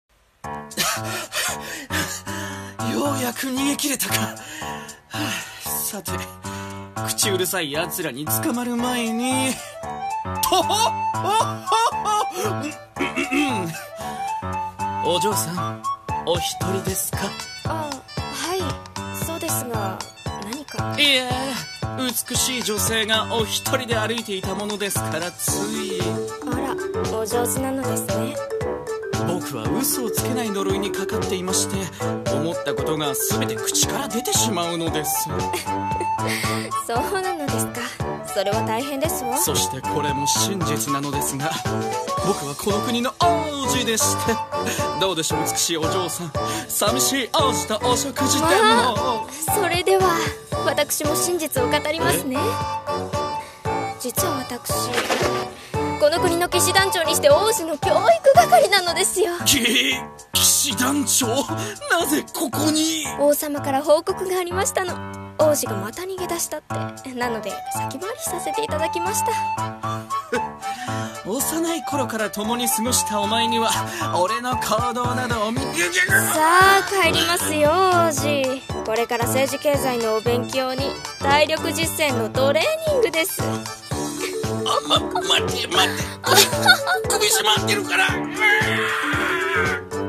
声劇台本 【城下町での忍び方】